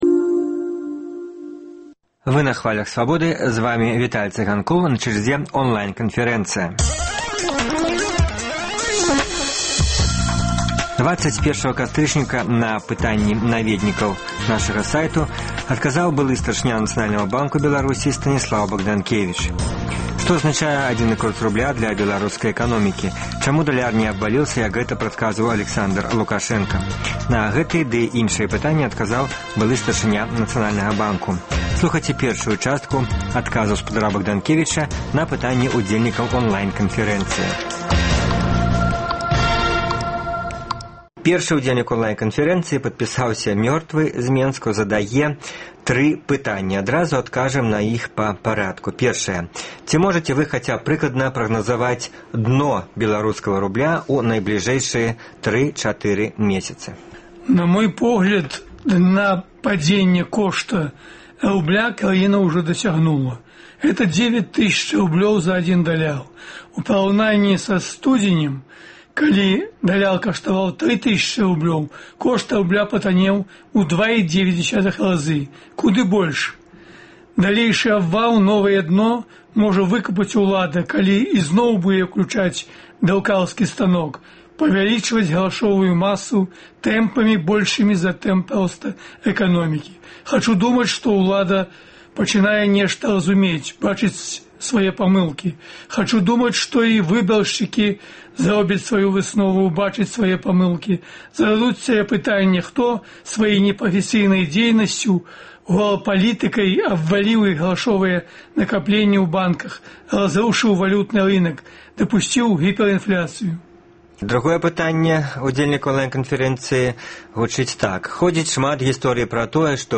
Онлайн-канфэрэнцыя
На пытаньні адказвае былы старшыня Нацбанку, ганаровы старшыня Аб'яднанай грамадзянскай партыі Станіслаў Багданкевіч.